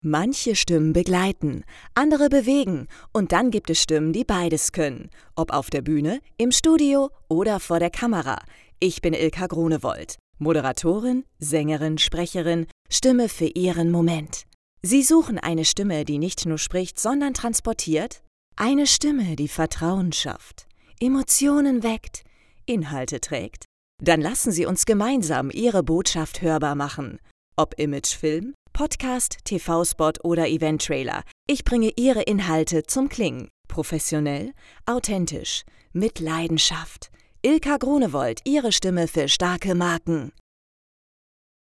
Eventtrailer
Audioguide
Norddeutsch
Dutch